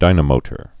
(dīnə-mōtər)